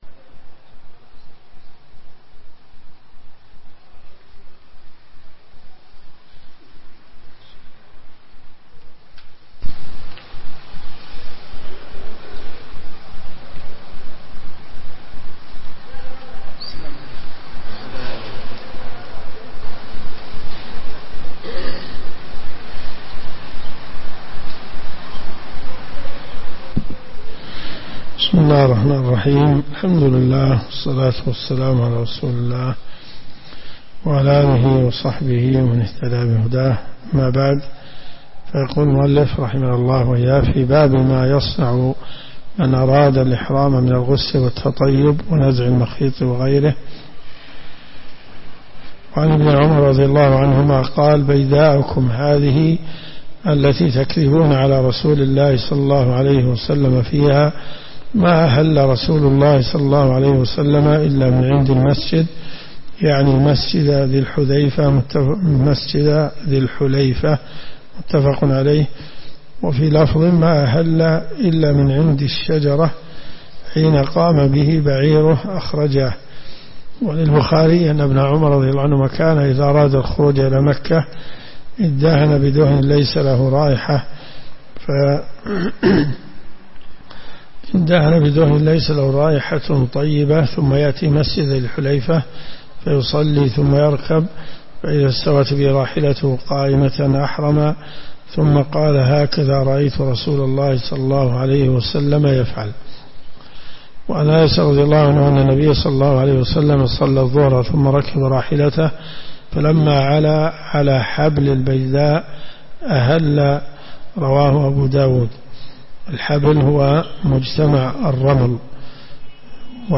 الرئيسية الكتب المسموعة [ قسم أحاديث في الفقه ] > المنتقى من أخبار المصطفى .